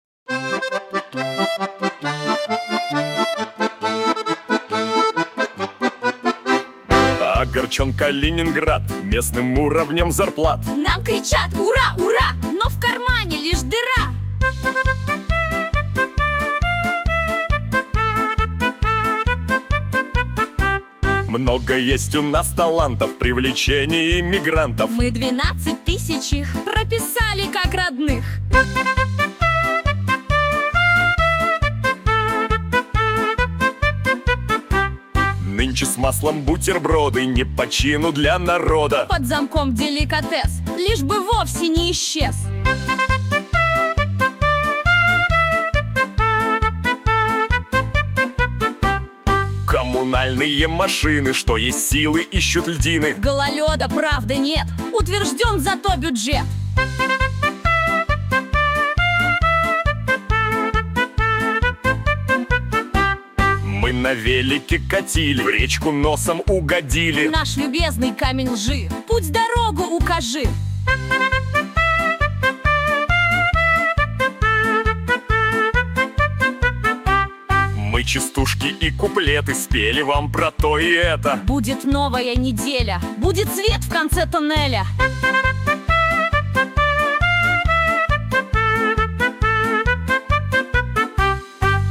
О главных и важных событиях — в виде комических куплетов